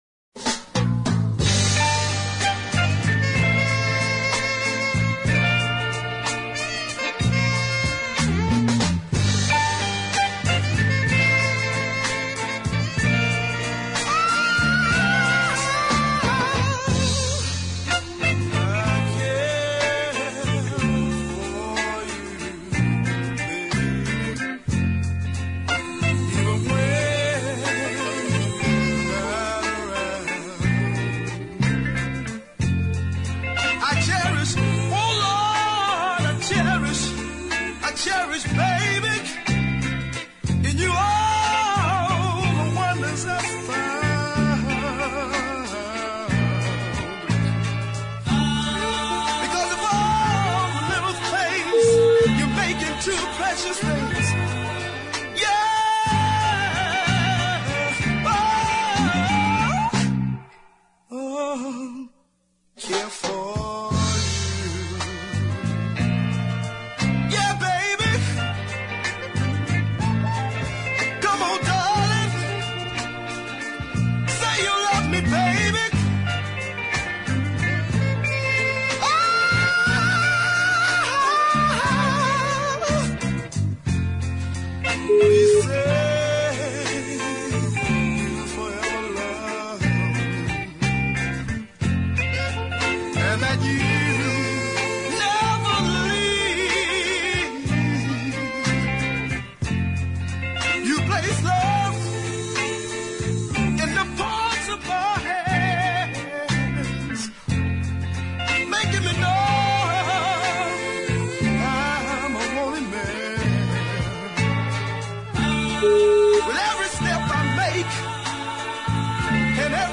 haunting little gem